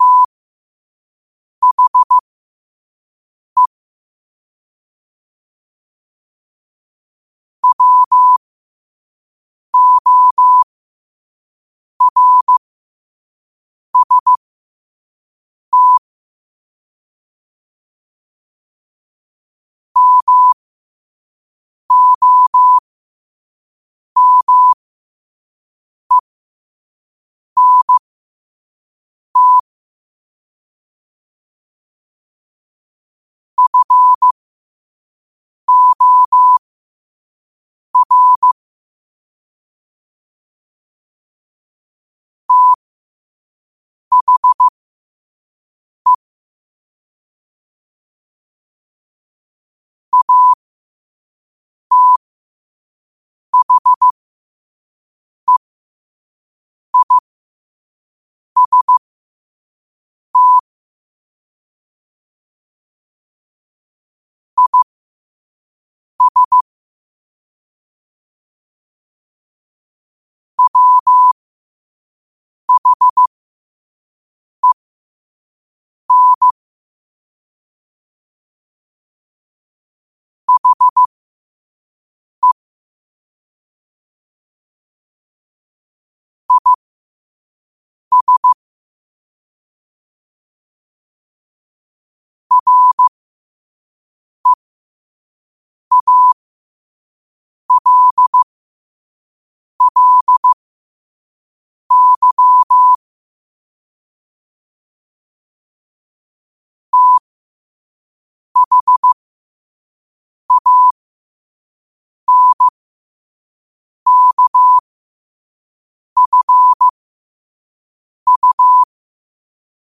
Quotes for Fri, 15 Aug 2025 in Morse Code at 5 words per minute.